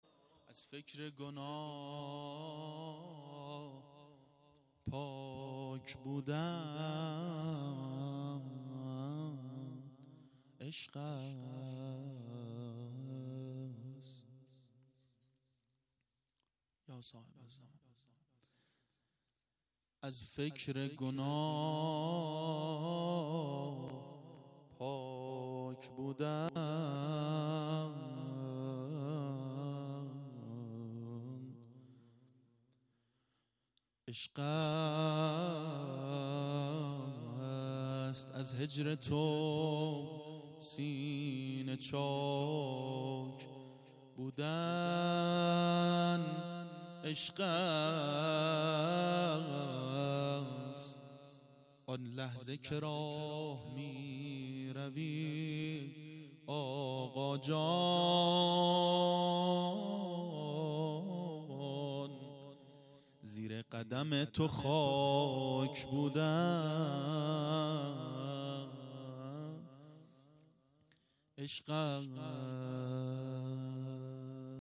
• میلاد پیامبر و امام صادق علیهماالسلام 92 هیأت عاشقان اباالفضل علیه السلام منارجنبان